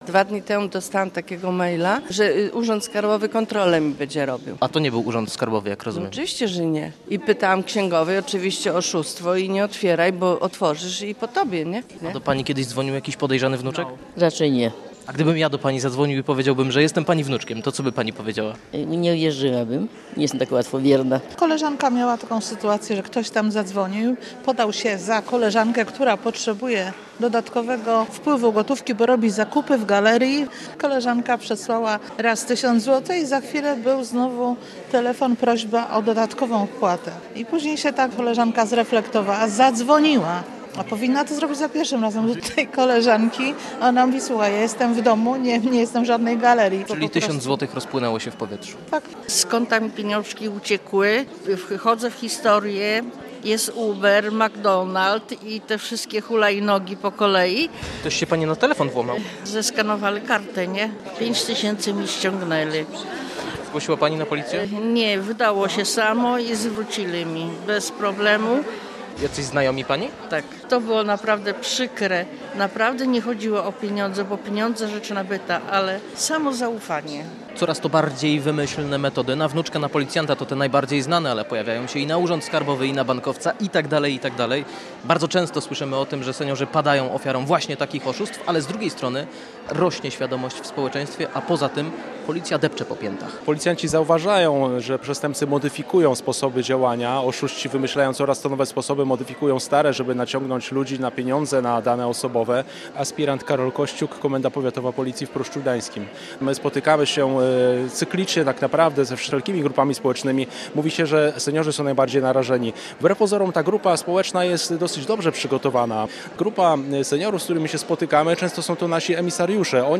Policjanci wcielili się profesorów, a seniorzy w uczniów. Wykład na temat oszustw w Pruszczu Gdańskim